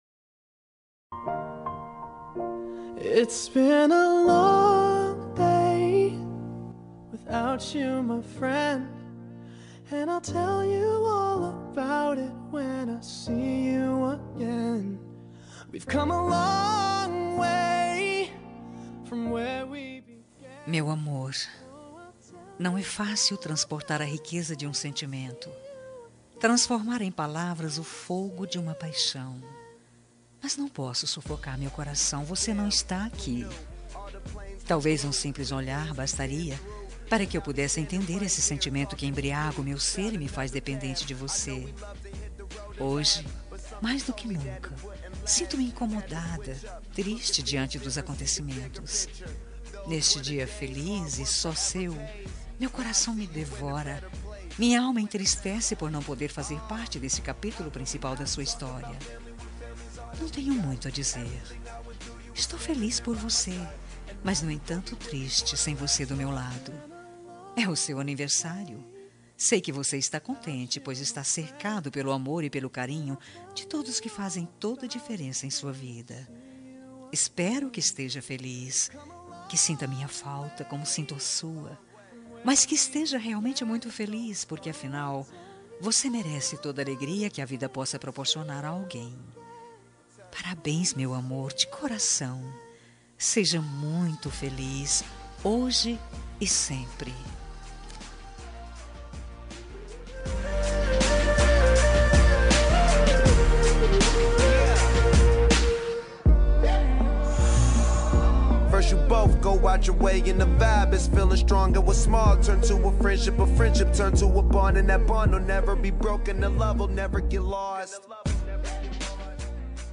Aniversário Distante – Romântica – Feminina – Cód: 8896